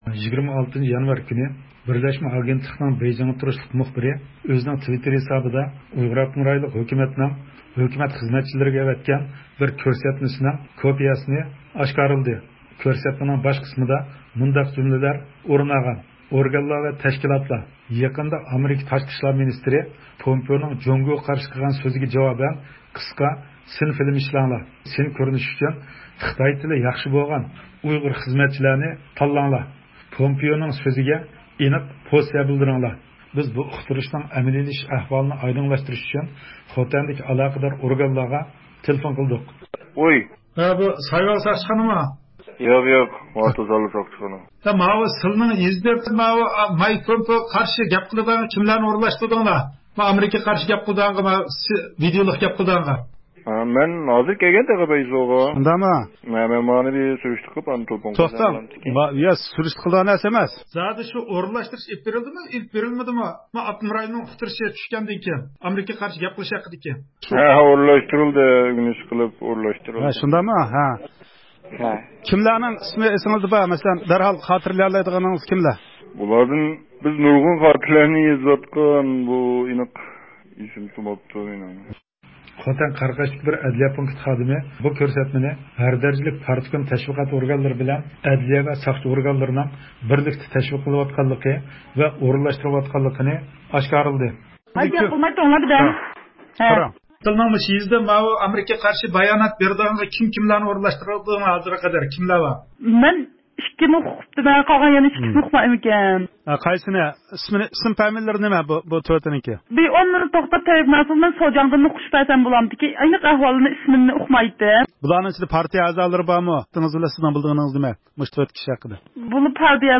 بىز بۇ ئۇقتۇرۇشنىڭ ئەمەلىيلىشىش ئەھۋالىنى ئايدىڭلاشتۇرۇش ئۈچۈن خوتەندىكى ئالاقىدار ئورگانلارغا تېلېفون قىلدۇق.